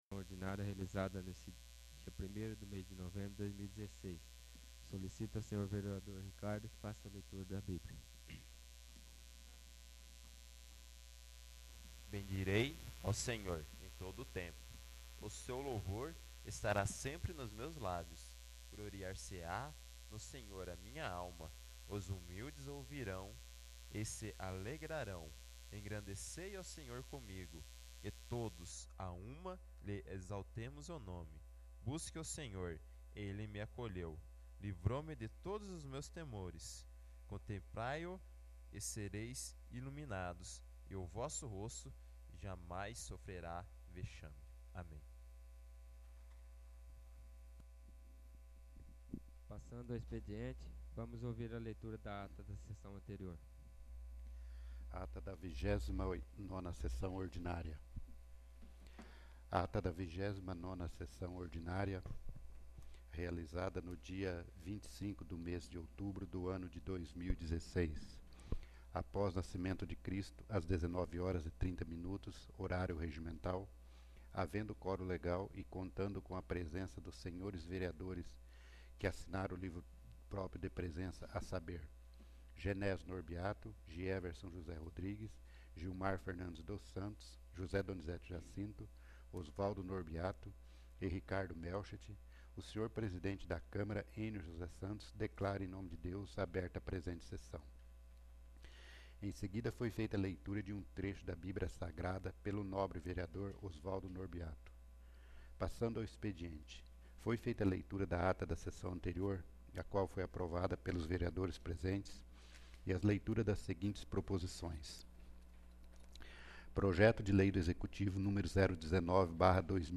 30º. Sessão Ordinária